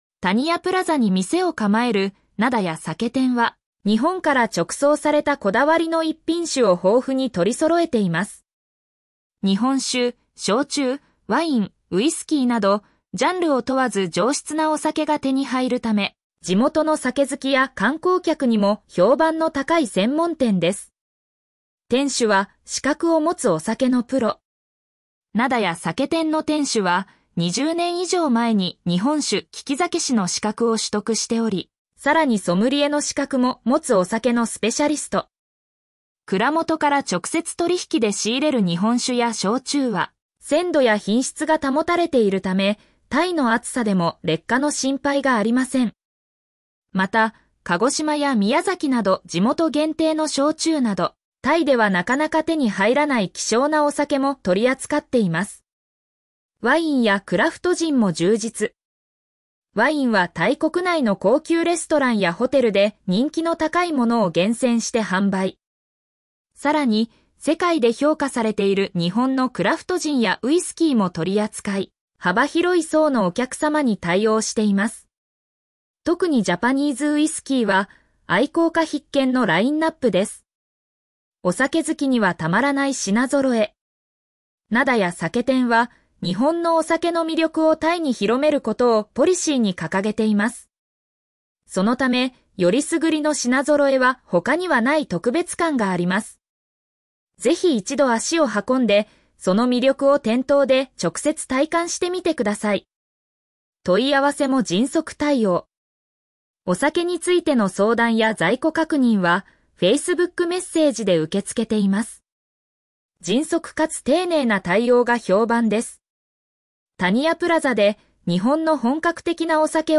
読み上げ タニヤプラザに店を構える「なだや酒店」は、日本から直送されたこだわりの逸品酒を豊富に取り揃えています。